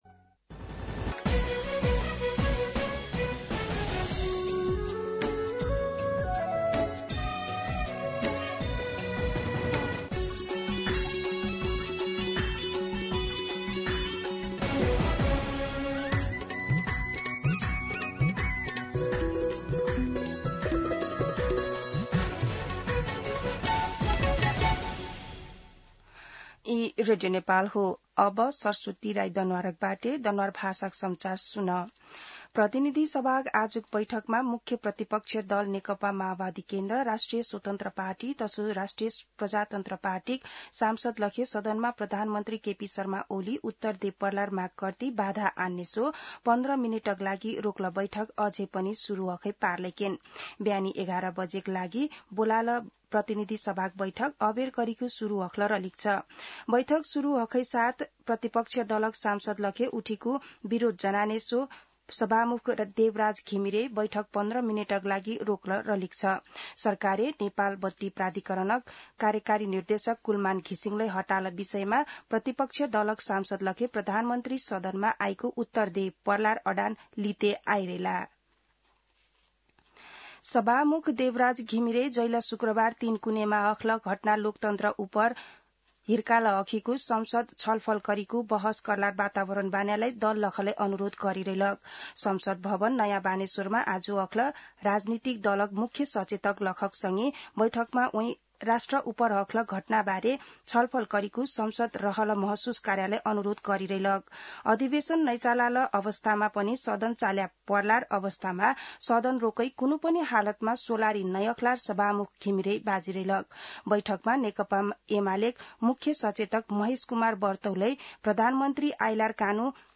दनुवार भाषामा समाचार : १७ चैत , २०८१